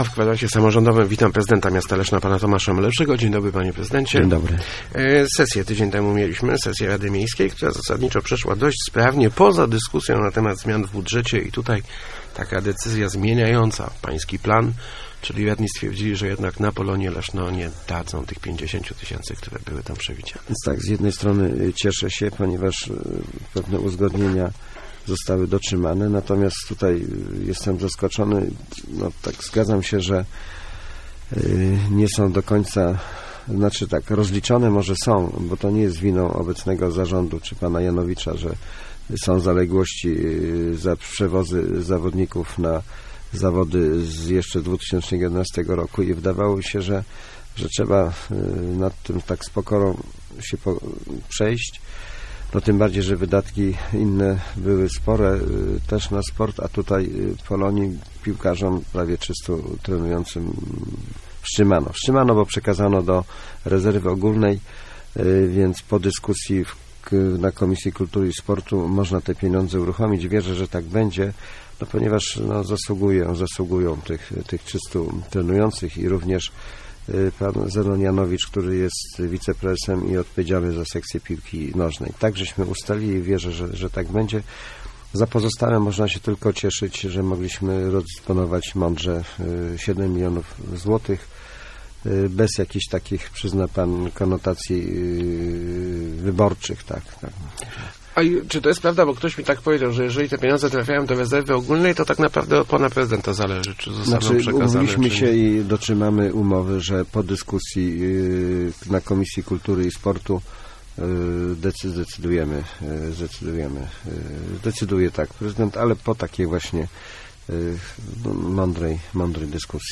Gościem Kwadransa był prezydent Tomasz Malepszy.